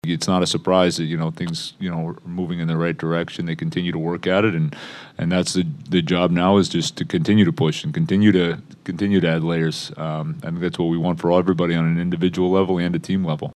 Muse says Arturs Silovs continues to grow in his rookie season.